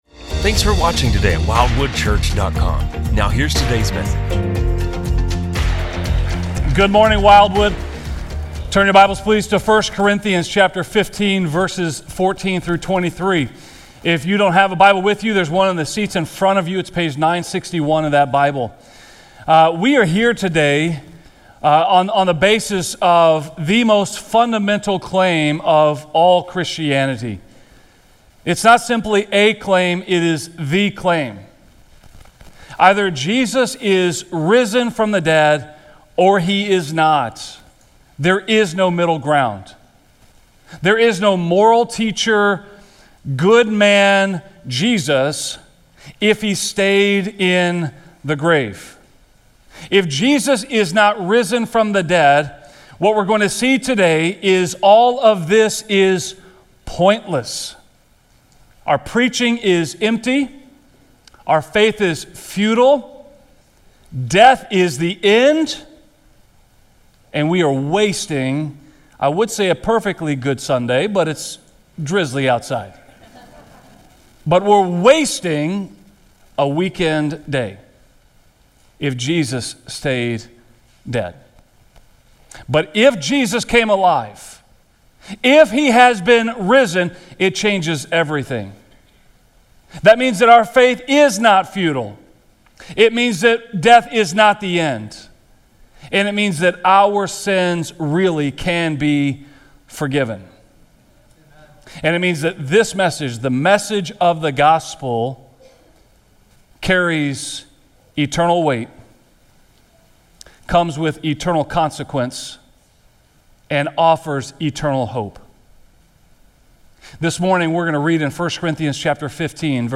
Easter 2020
From Series: "Stand Alone Sermons"